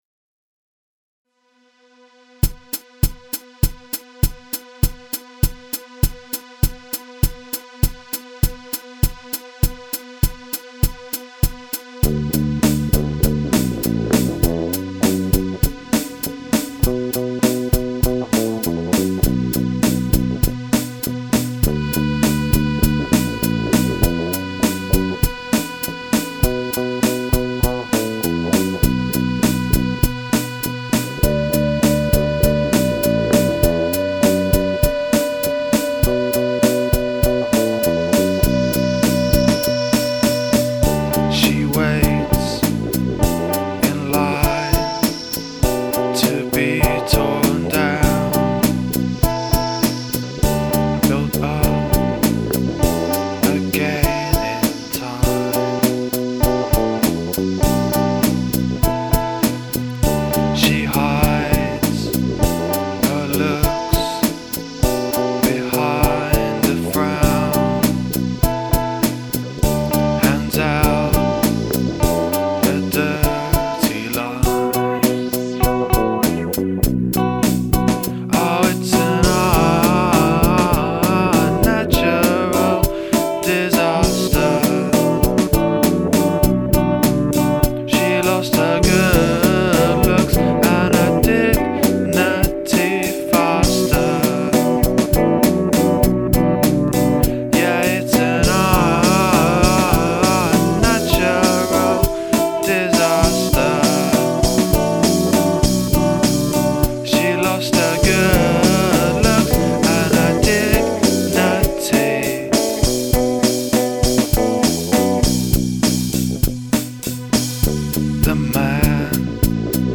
Must include prominent use of backwards recording
The tired sounding vocals fit quite well with this song.